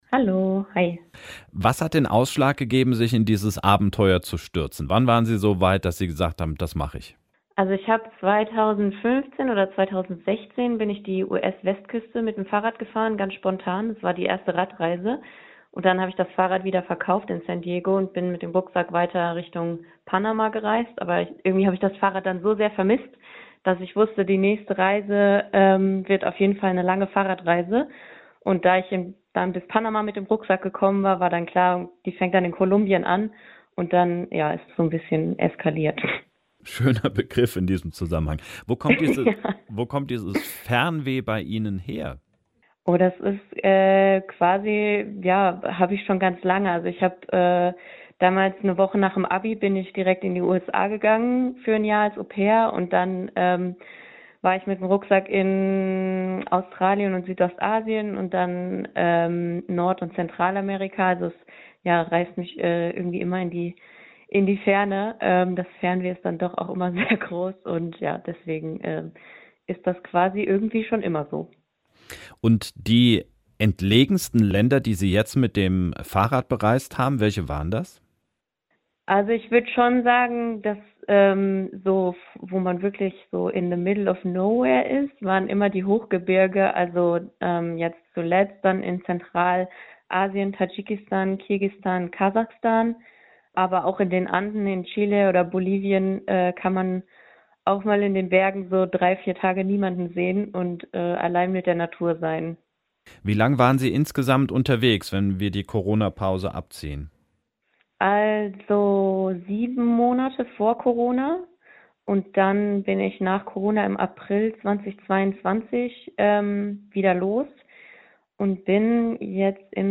Hier gibt es das ungekürzte Interview.